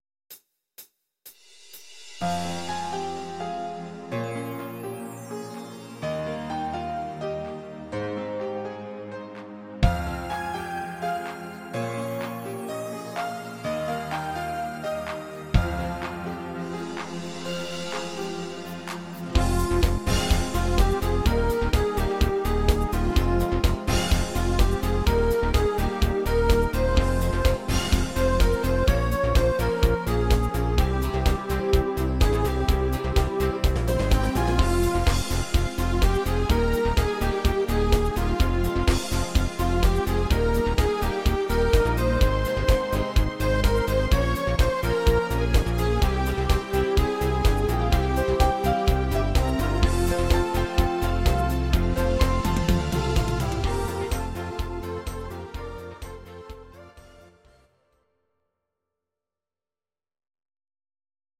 Audio Recordings based on Midi-files
German, Volkstï¿½mlich